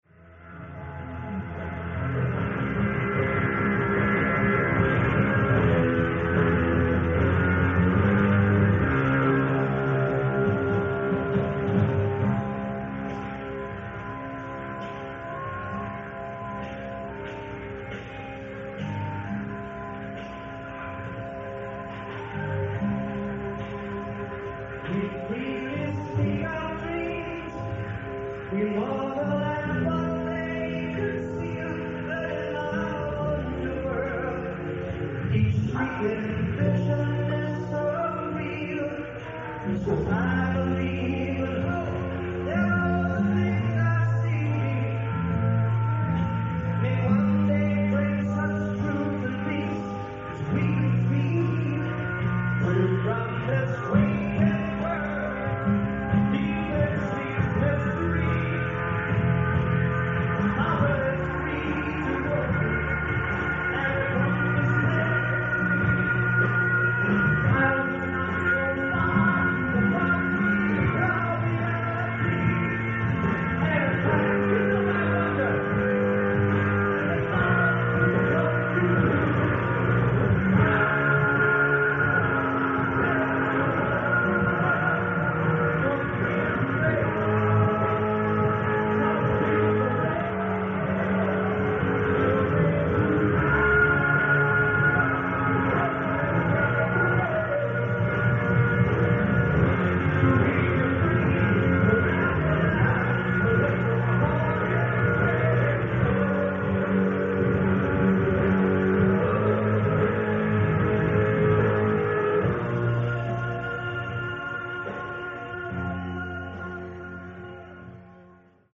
Venue:  Festival Hall
Sound:  Remastered
Source:  Audience Recording